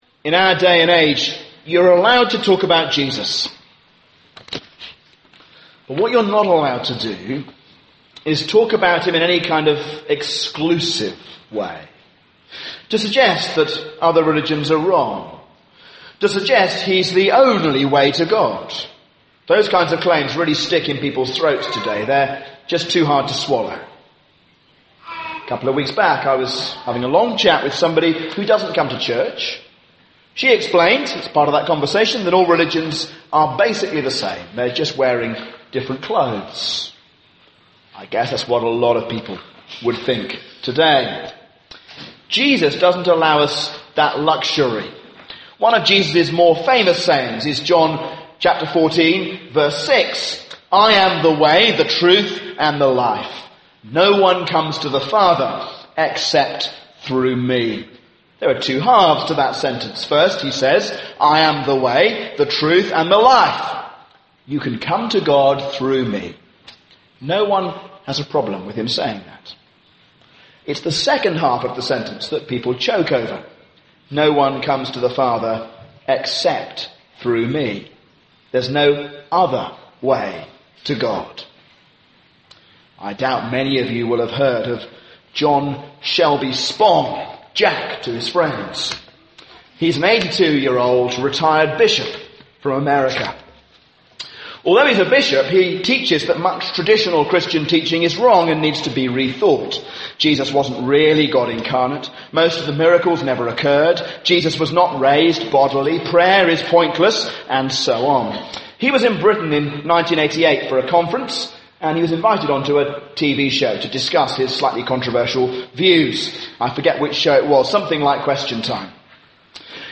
A sermon on Numbers 17